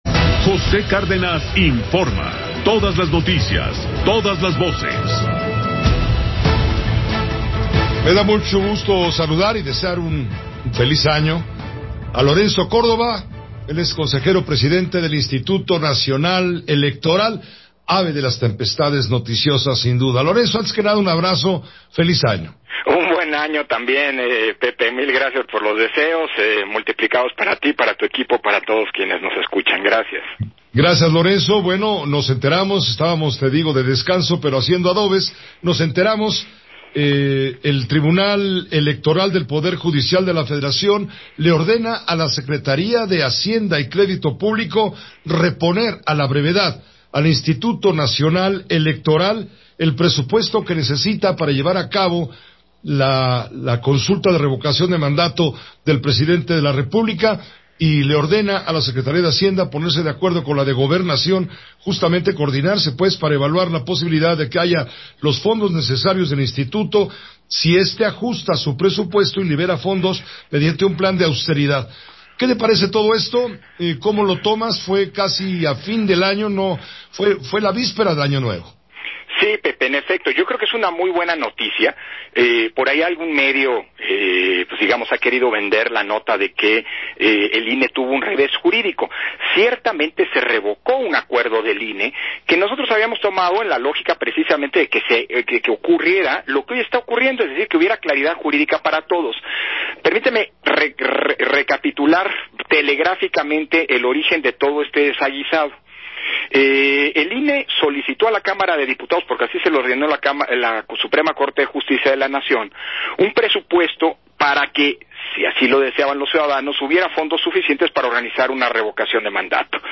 Buena noticia resolución del TEPJF respecto a acuerdo del INE; Lorenzo Córdova en entrevista con José Cárdenas